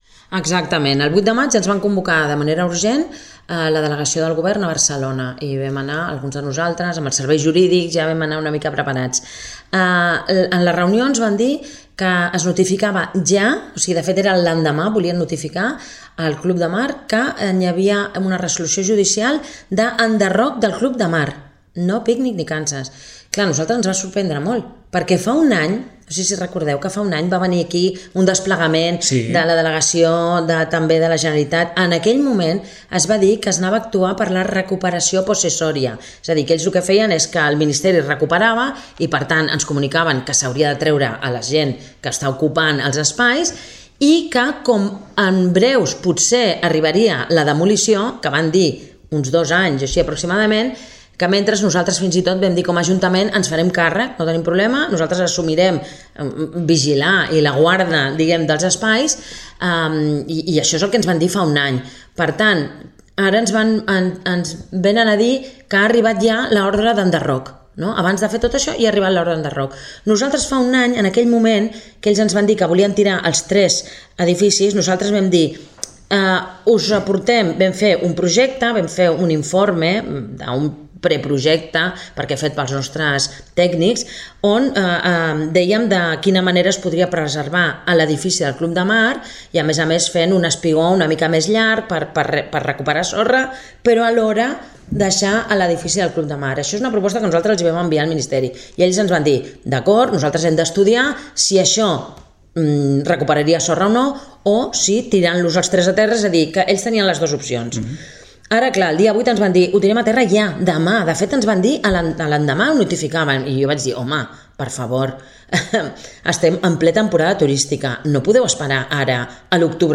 L’alcaldessa n’ha explicat els detalls.